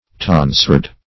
Tonsured \Ton"sured\, a.